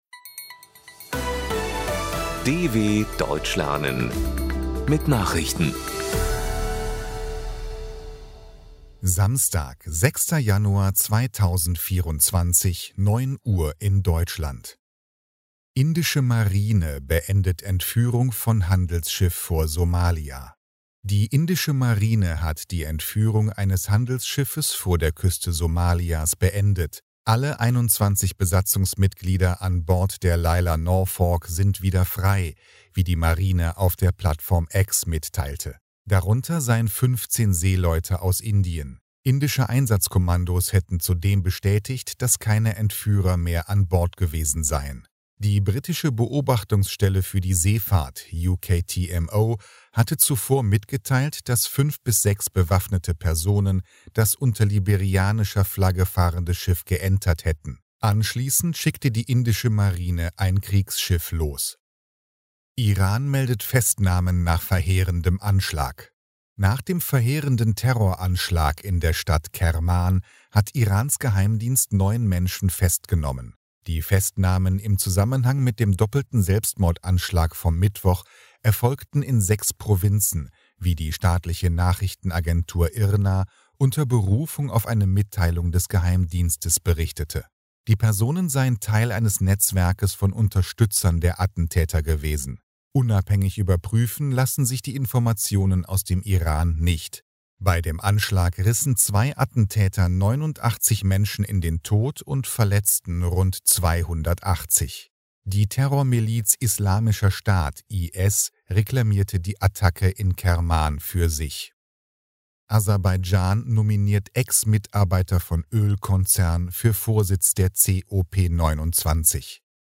06.01.2024 – Langsam Gesprochene Nachrichten
Trainiere dein Hörverstehen mit den Nachrichten der Deutschen Welle von Samstag – als Text und als verständlich gesprochene Audio-Datei.